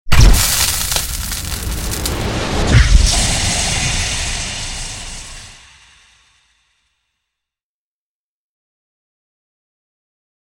Шепот магического зелья яда